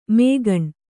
♪ mēgaṇ